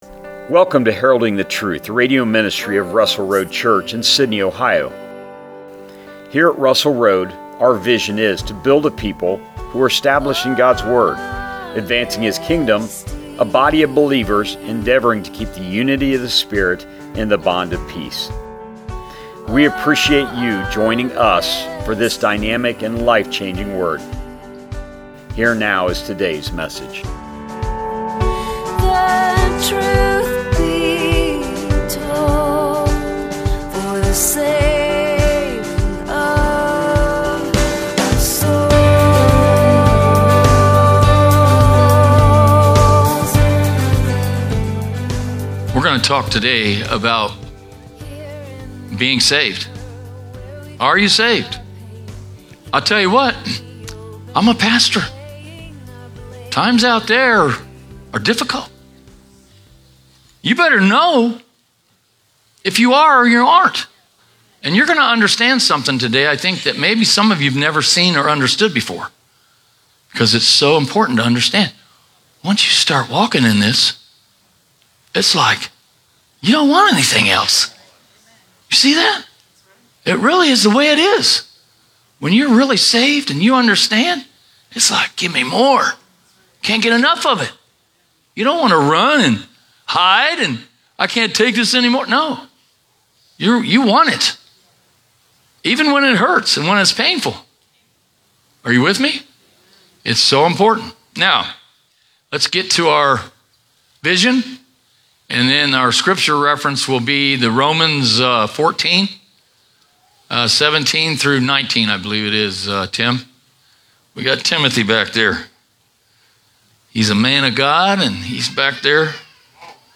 Sermons | Russell Road Church